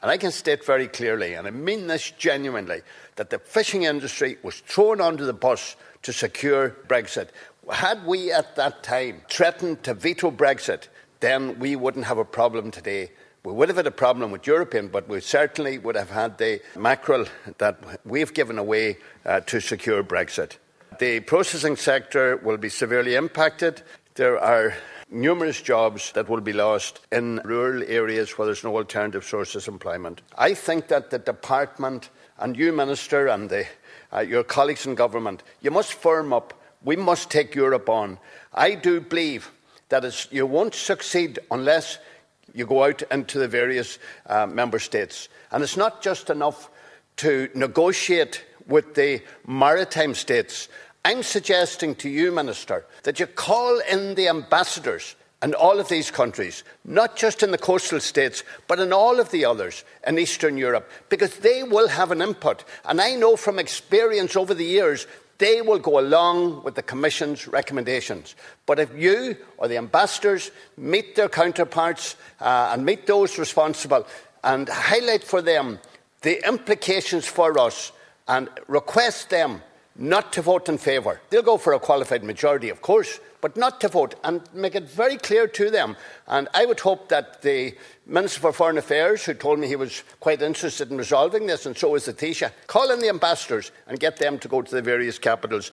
Donegal Deputy Pat the Cope Gallagher has told the Dail that he believes the Irish fishing sector was thrown under the bus in order to secure a Brexit deal, and that’s why the industry is now facing potential Armageddon.
Deputy Gallagher urged Fisheries Minister Timmy Dooley to launch a diplomatic offensive encouraging other member states to oppose the proposed quota cuts: